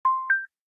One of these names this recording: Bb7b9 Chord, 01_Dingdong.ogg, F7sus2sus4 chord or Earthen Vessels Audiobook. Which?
01_Dingdong.ogg